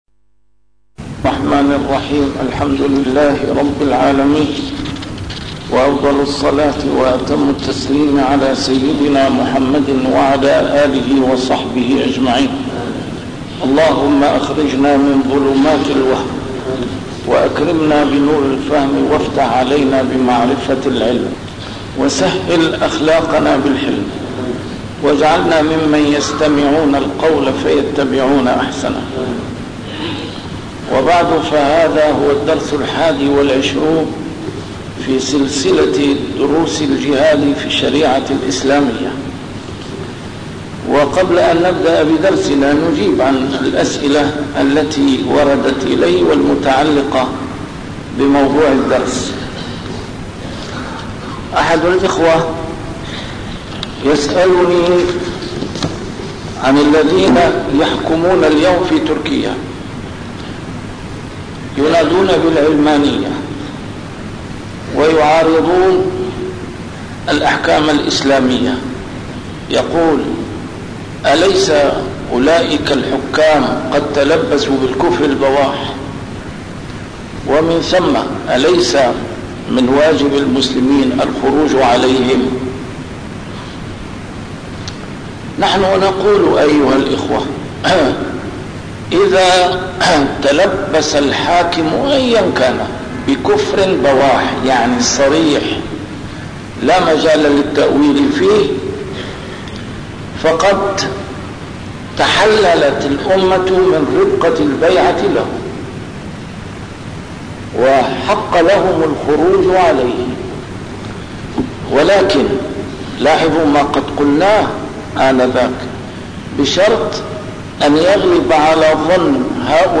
A MARTYR SCHOLAR: IMAM MUHAMMAD SAEED RAMADAN AL-BOUTI - الدروس العلمية - الجهاد في الإسلام - تسجيل قديم - الدرس الواحد والعشرون: سياسة التفكيك التي تتبعها الدول الكبرى في المنطقة